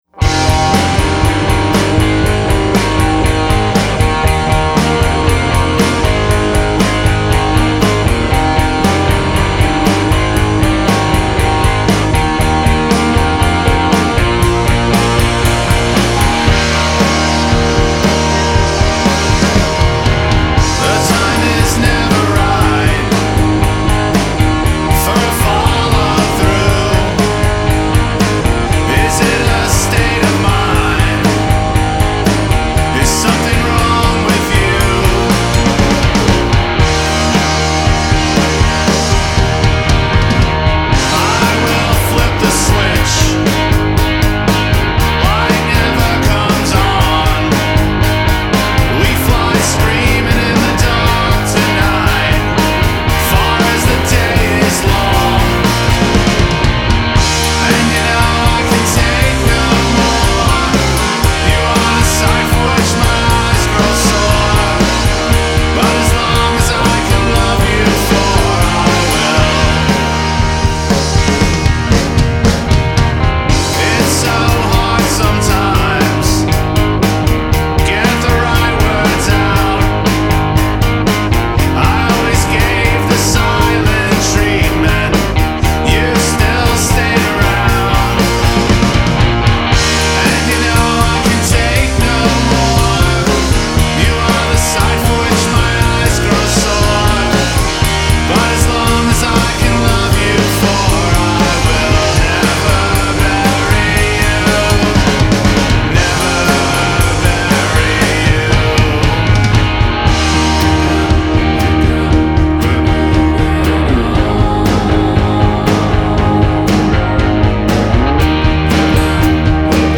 What post-punk is supposed to sound like.